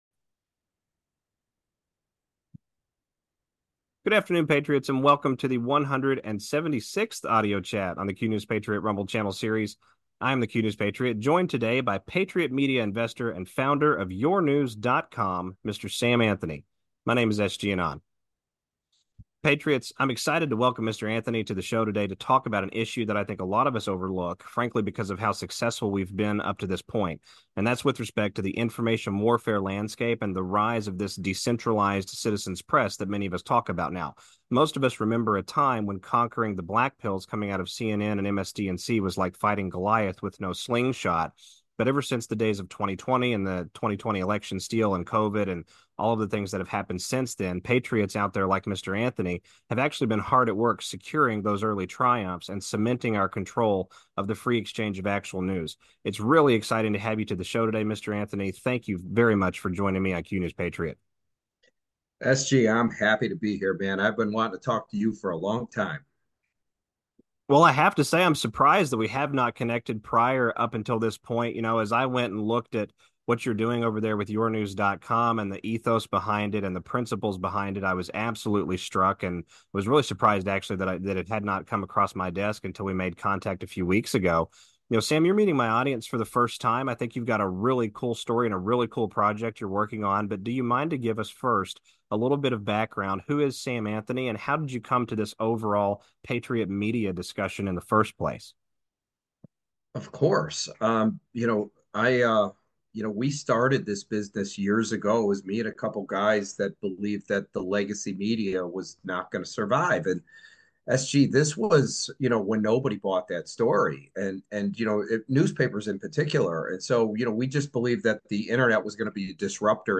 AUDIO CHAT 176